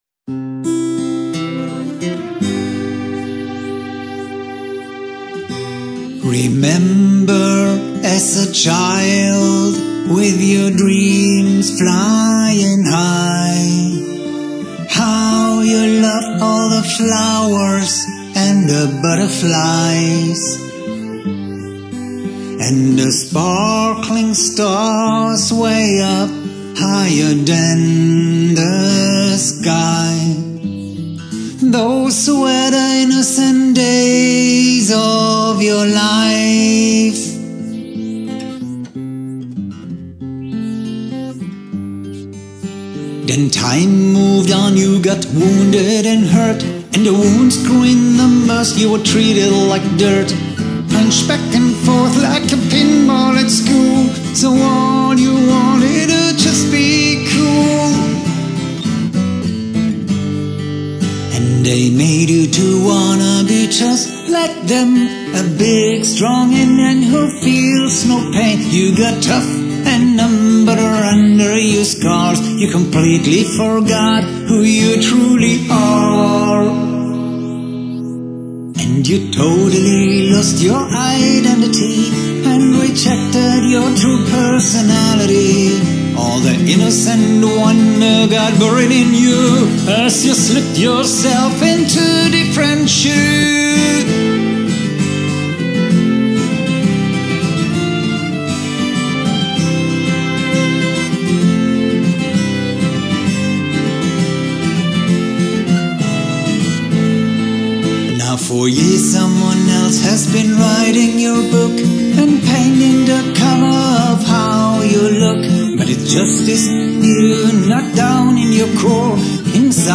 Life story with nice suspense music
Unplugged Version